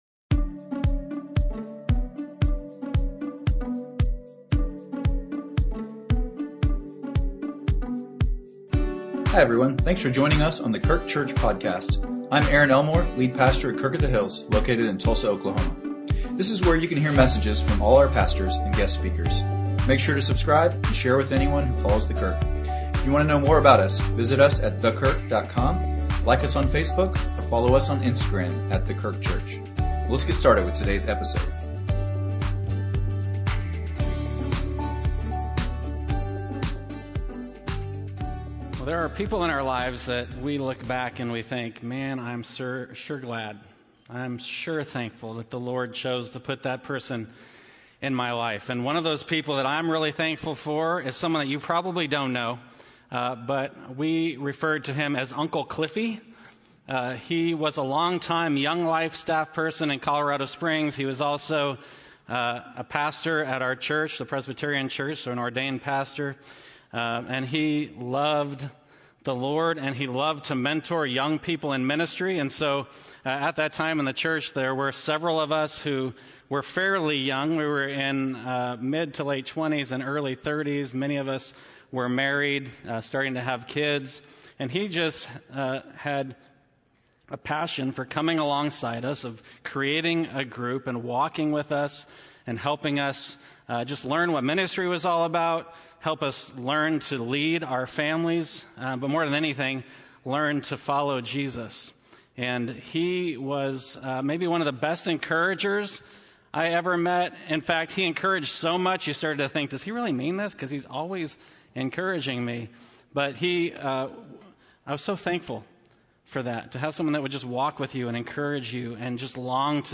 A message from the series "Standing Strong."